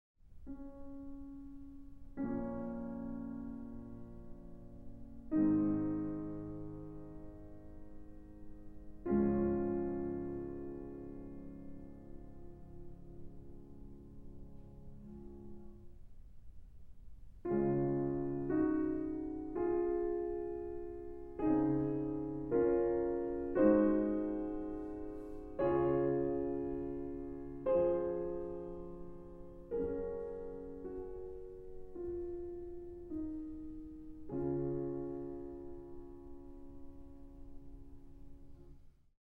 Arrangement for 2 pianos, 8 hands